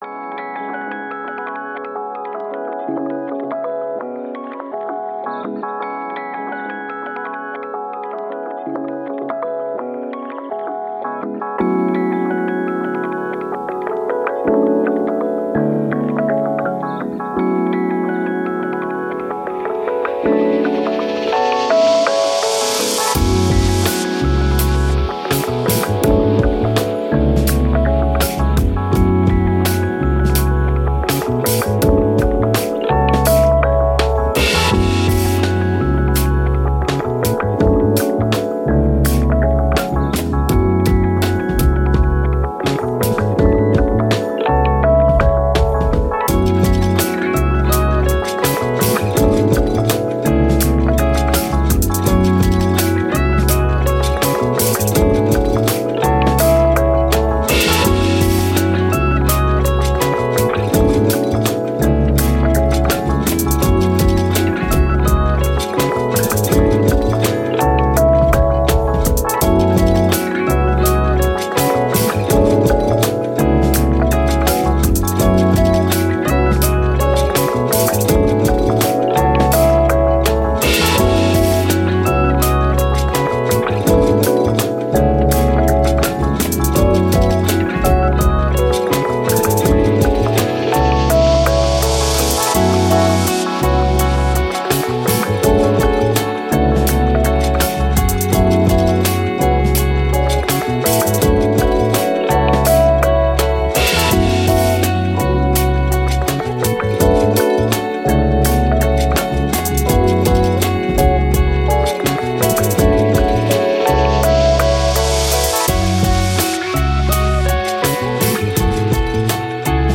Downtempo, Electronic, Glitch, Story